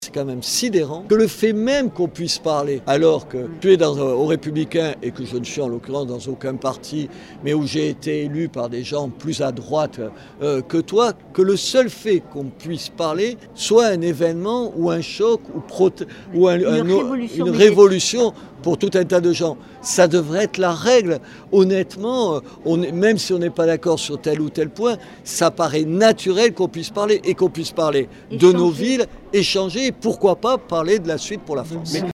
Brigitte Barèges, maire LR de Montauban, et Robert Ménard, maire de Béziers soutenu fut un temps par le Rassemblement National, posaient hier côte-à-côte et laissaient croître - et croire à - une union des droites.
Devant un public de 300 personnes acquis à leur cause, tous deux se sont attachés à montrer leurs convergences, au point de se demander s’il ne s’agissait pas d’un coup d ‘essai pour les prochaines échéances. Insécurité, police municipale, immigration, ils sont d'accord sur tout ou presque.